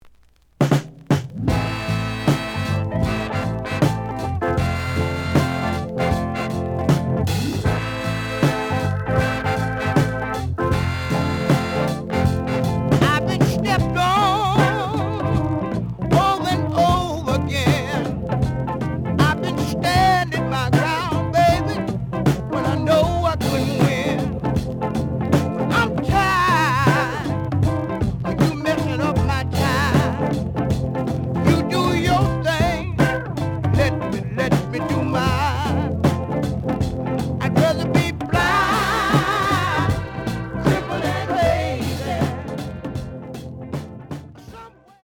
The audio sample is recorded from the actual item.
●Genre: Soul, 70's Soul
Slight noise on A side.